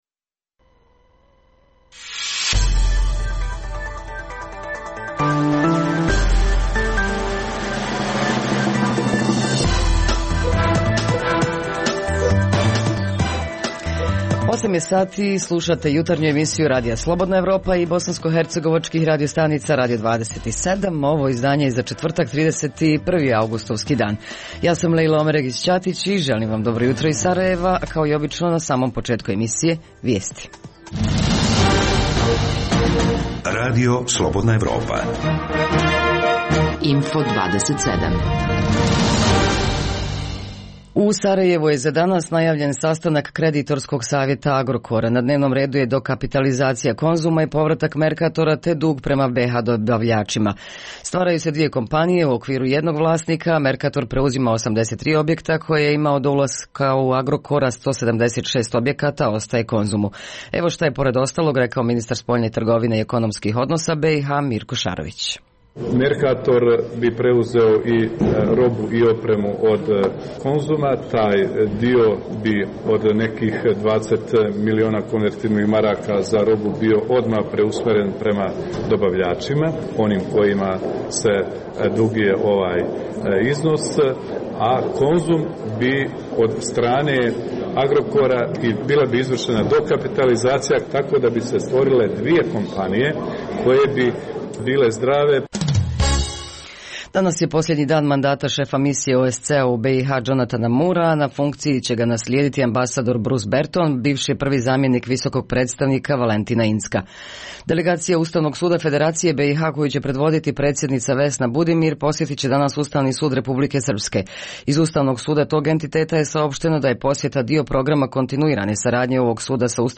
O tome više od naših dopisnika iz Travnika, Mostara, Banja Luke i Doboja. U Milićima održana Ljetna škola hobija gdje je oko 100 osnovaca provelo posljednje dane raspusta.